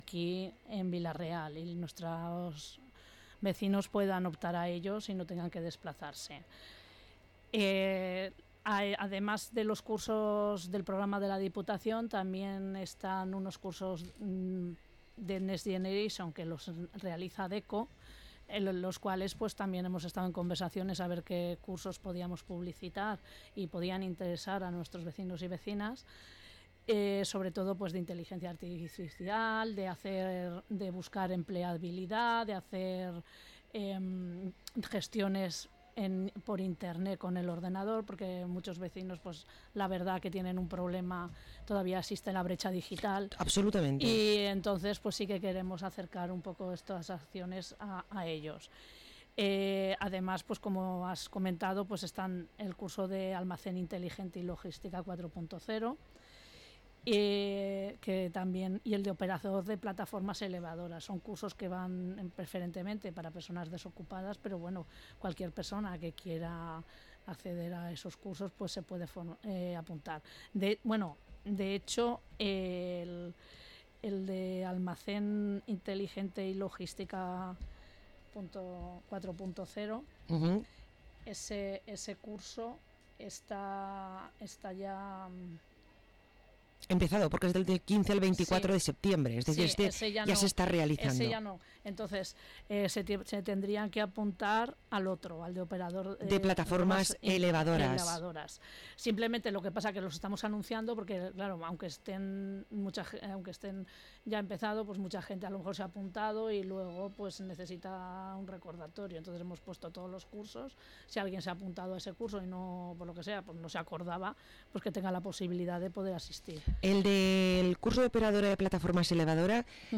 Parlem amb Ana Torres, regidora d´Economia a l´Ajuntament de Vila-real